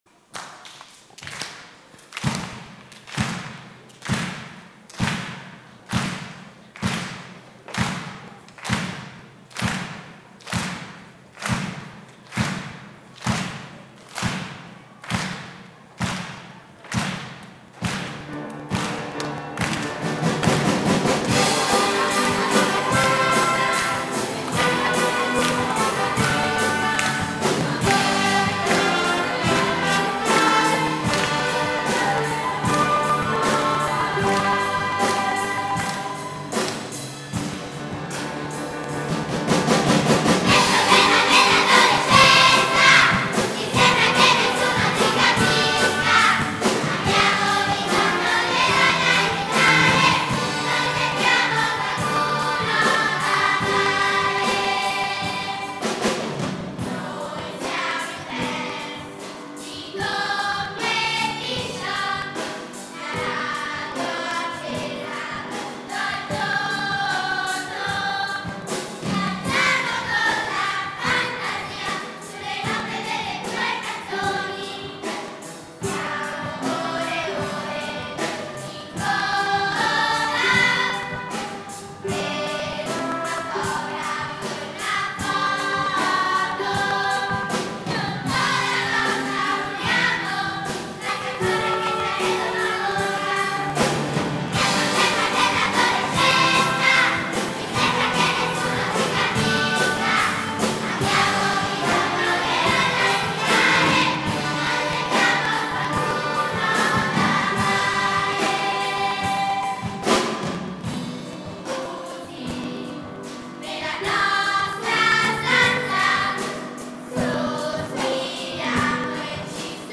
Audio dagli spettacoli e dai seminari in cui sono stati coinvolti gli allievi e gli insegnati dell'Accademia Musicale Giuseppe Verdi